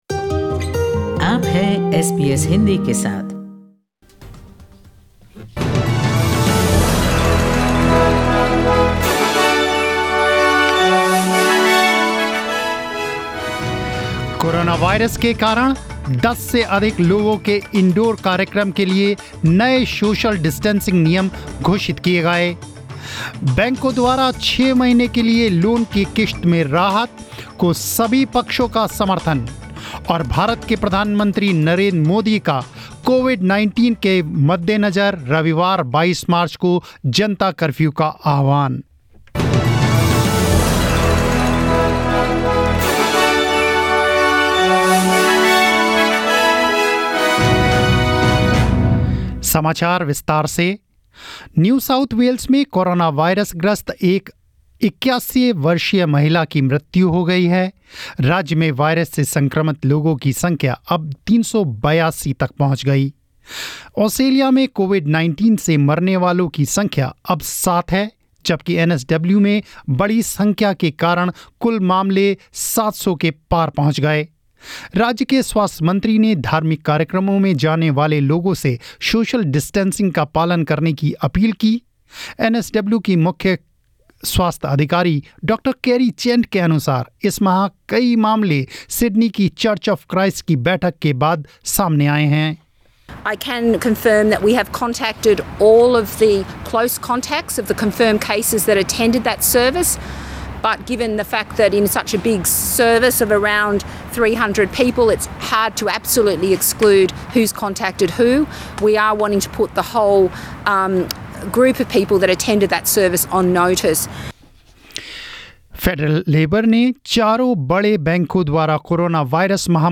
News in Hindi 20 march 2020